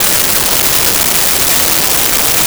Shopping Mall
Shopping Mall.wav